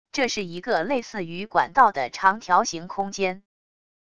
这是一个类似于管道的长条形空间wav音频